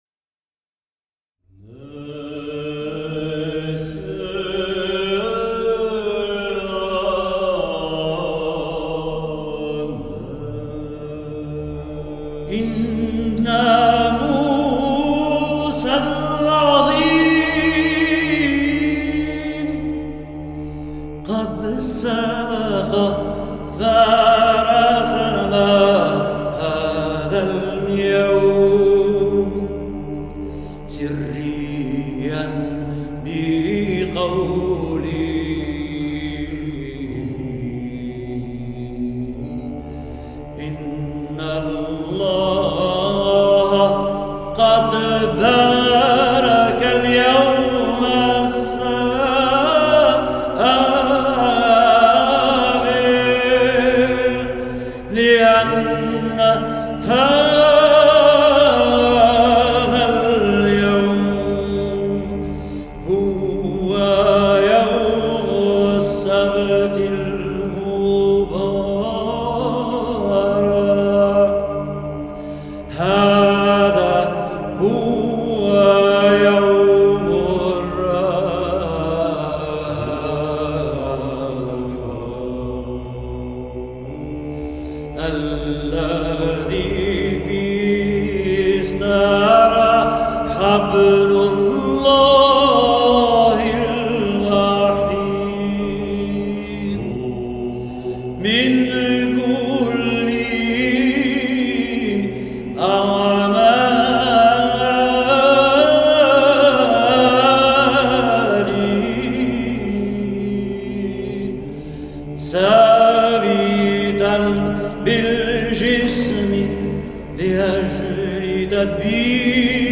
(liturgie byzantine, aux vêpres du Samedi Saint)